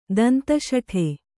♪ danta śaṭhe